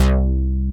BASS FLTOPEN.wav